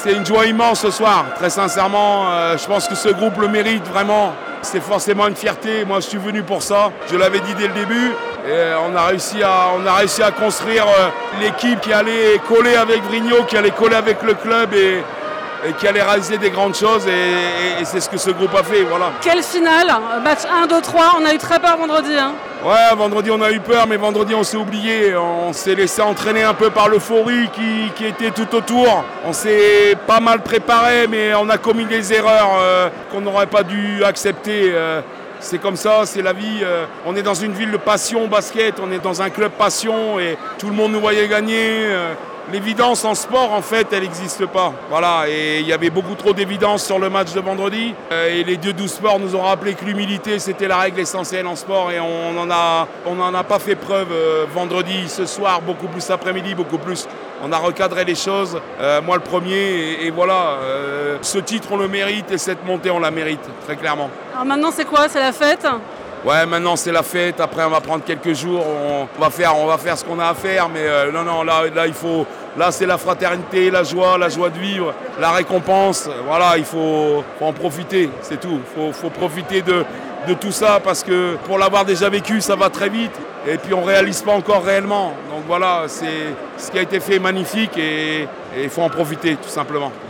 Reportage
La salle Michel Vrignaud a plus que vibré hier, lors du dernier match de la finale de NM1 entre Challans et Mulhouse.